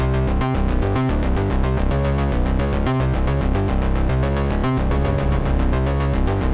Index of /musicradar/dystopian-drone-samples/Droney Arps/110bpm
DD_DroneyArp1_110-C.wav